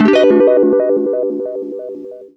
GUITARFX13-L.wav